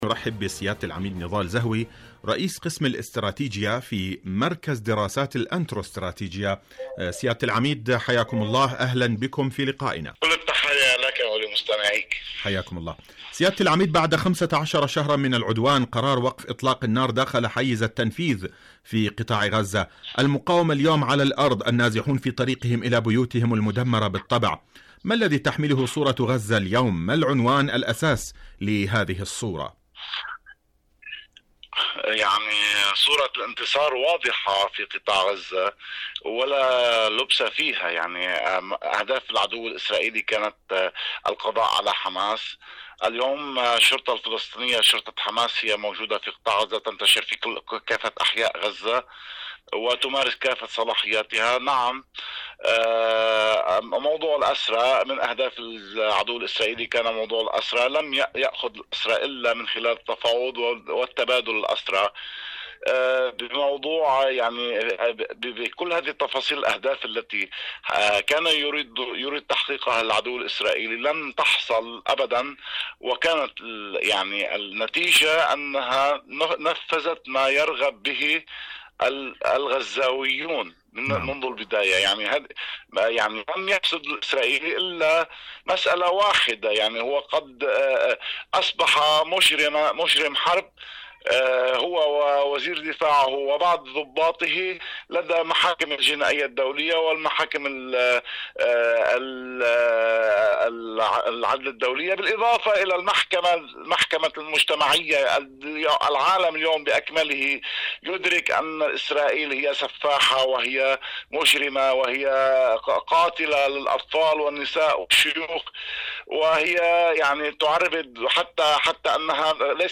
مقابلات إذاعية برنامج فلسطين اليوم